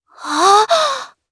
Nia-Vox_Happy4_jp.wav